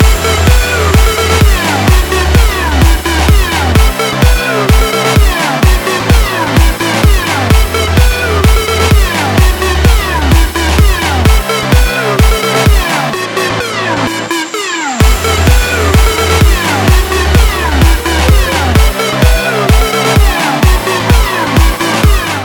• Качество: 192, Stereo
Необычный, приятный бит - хороший звонок для телефона!